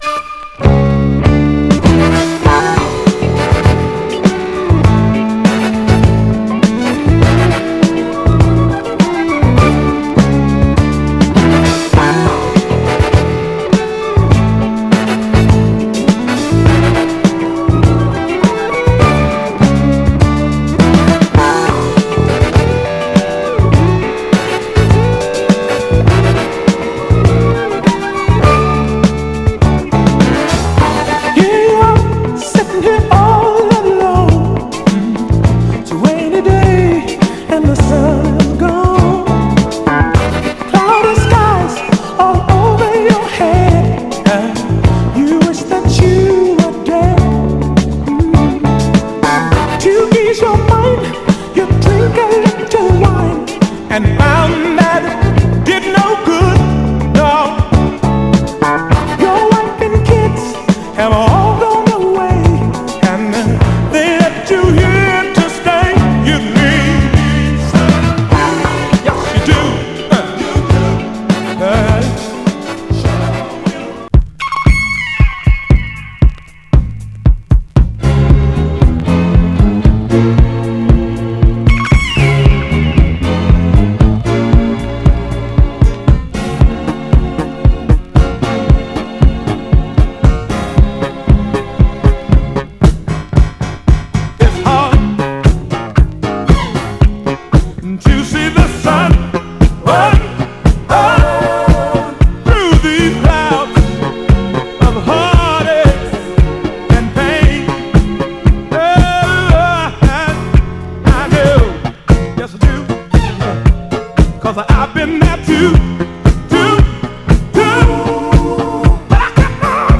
ブギーなモダン・ダンサー
両面共に序盤で少しチリつきますが、それ以降目立つノイズは少なくプレイ概ね良好です。
※試聴音源は実際にお送りする商品から録音したものです※